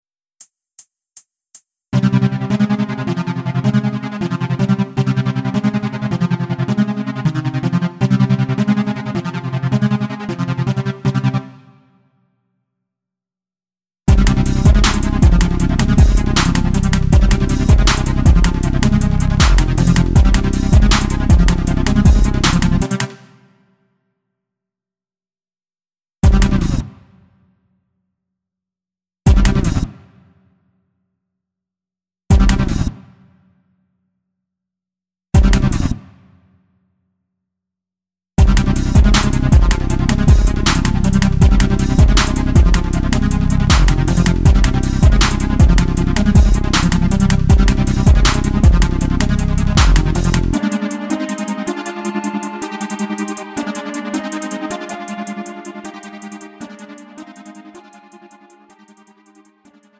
음정 -1키
장르 가요 구분 Lite MR
Lite MR은 저렴한 가격에 간단한 연습이나 취미용으로 활용할 수 있는 가벼운 반주입니다.